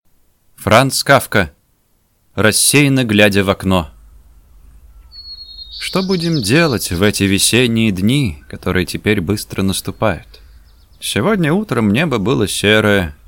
Аудиокнига Рассеянно глядя в окно | Библиотека аудиокниг
Прослушать и бесплатно скачать фрагмент аудиокниги